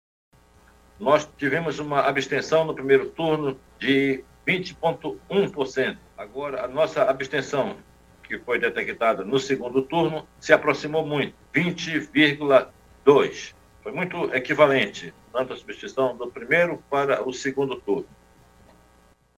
Durante coletiva de imprensa, órgãos envolvidos nas Eleições 2022 divulgaram dados.
Sonora-desembargador-Jorge-Lins-presidente-do-TRE-AM.mp3